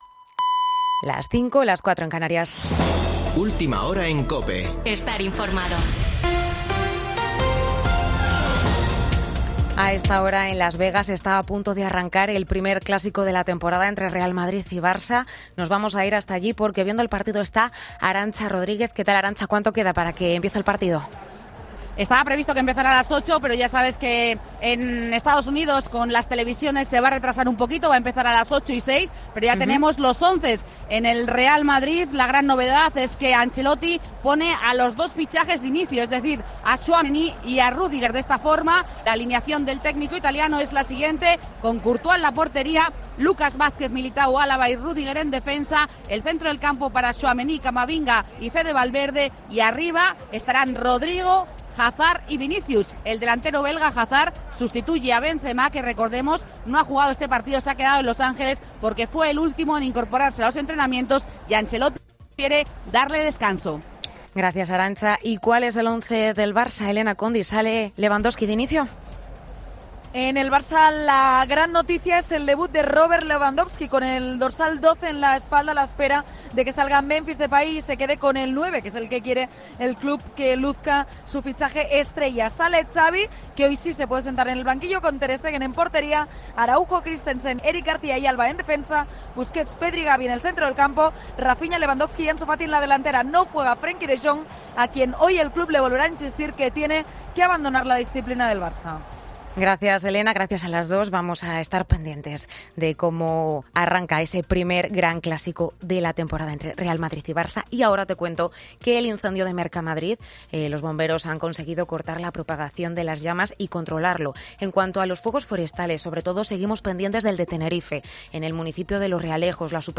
Boletín de noticias de COPE del 24 de julio de 2022 a las 05:00 horas